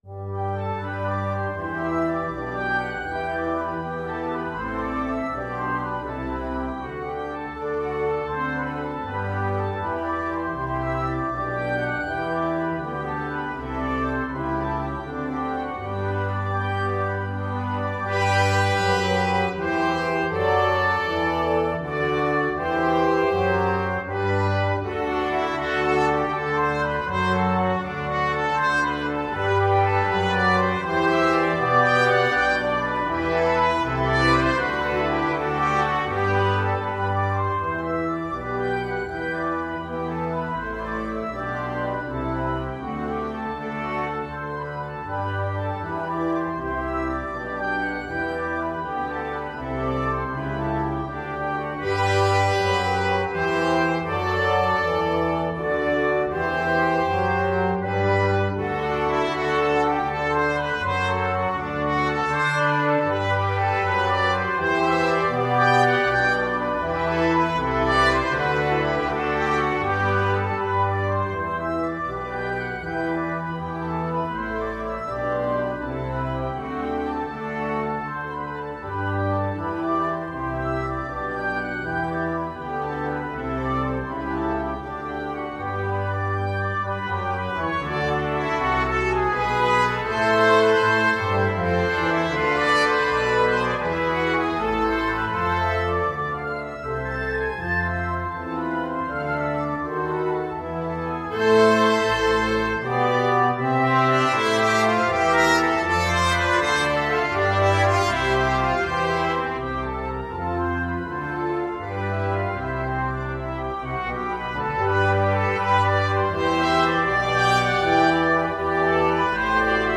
3/4 (View more 3/4 Music)
Andante